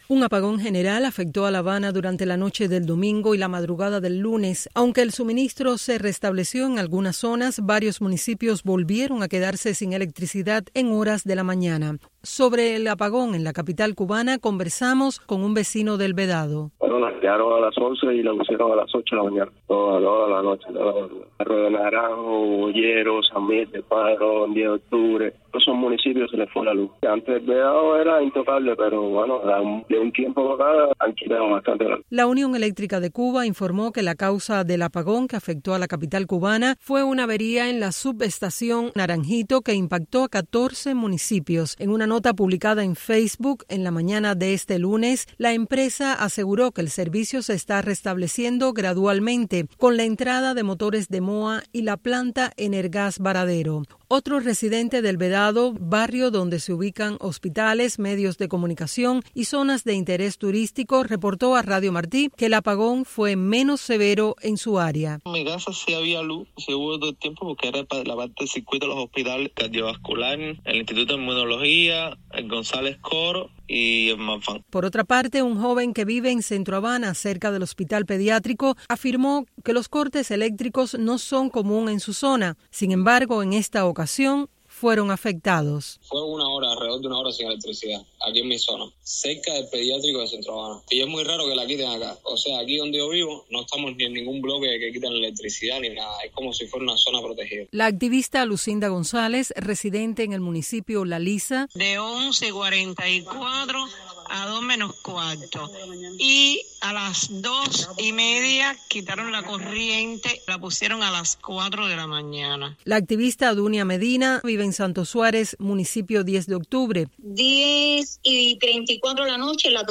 El testimonio de los vecinos de La Habana revela la magnitud del apagón
Cortes sin previo aviso y "zonas protegidas" que pierden su privilegio. Residentes relatan el caos y la frustración tras apagón general.